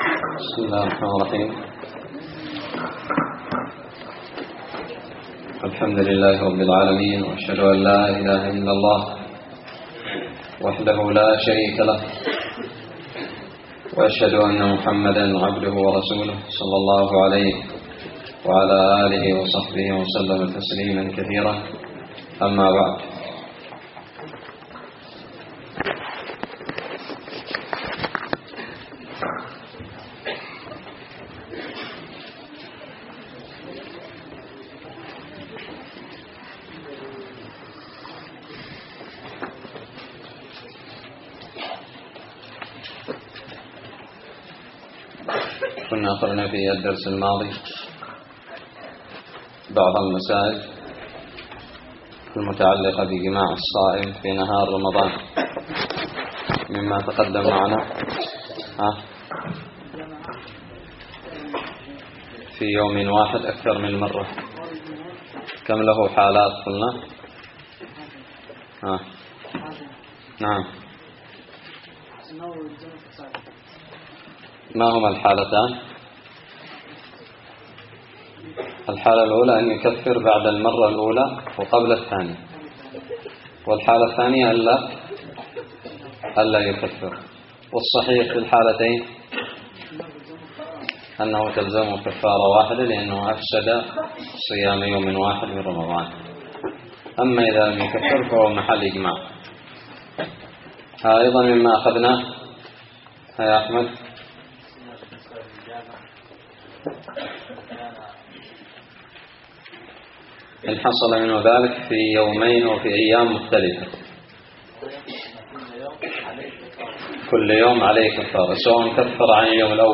الدرس الحادي والثلاثون من كتاب الصيام من الدراري
ألقيت بدار الحديث السلفية للعلوم الشرعية بالضالع